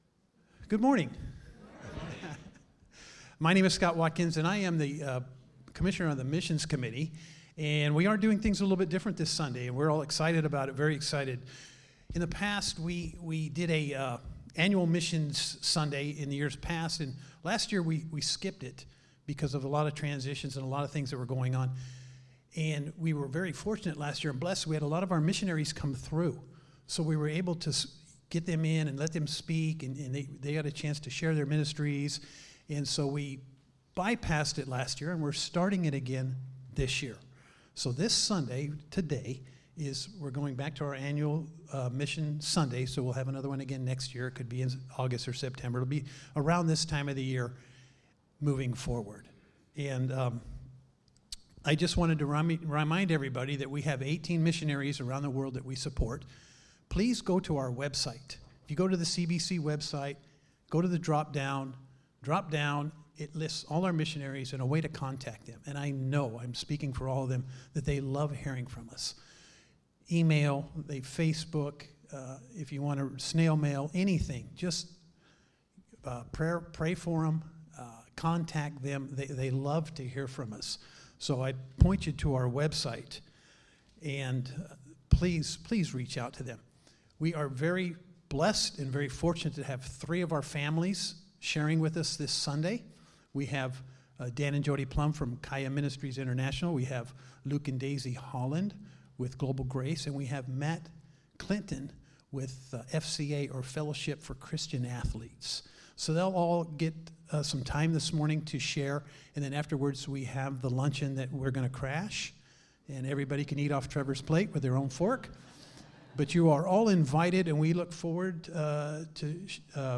Speaker: Guests Series: Missions Passage: Missions Service Type: Sunday Get ready for an inspiring morning as we celebrate Mission Impact Sunday!